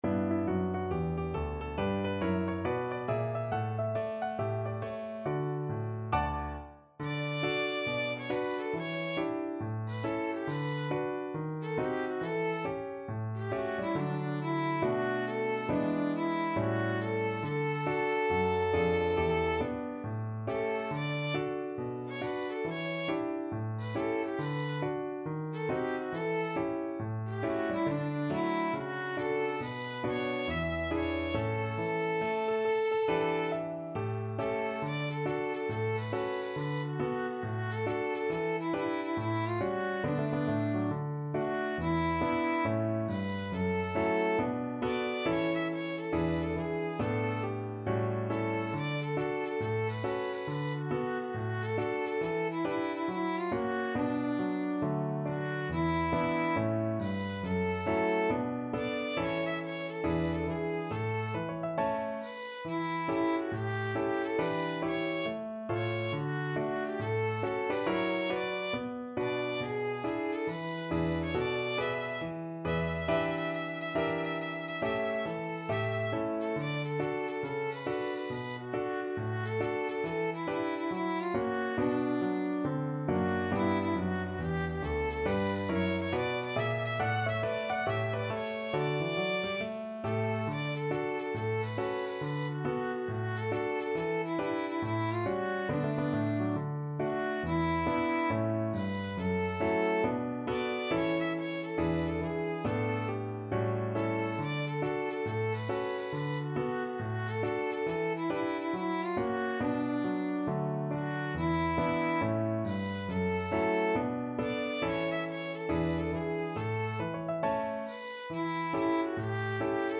With a swing =c.69
Pop (View more Pop Violin Music)